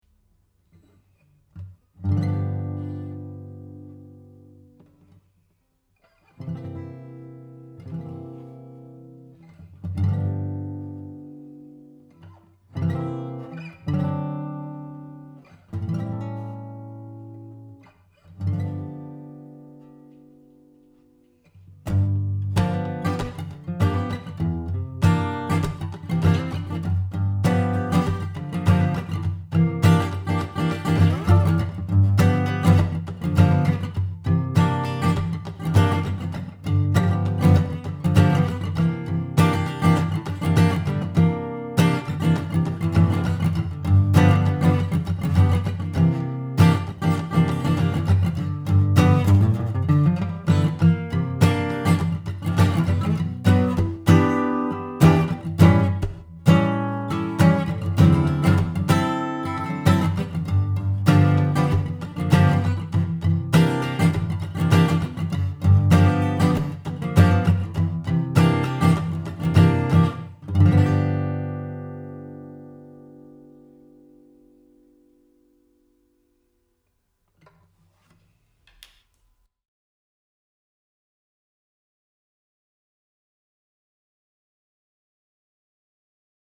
strum.mp3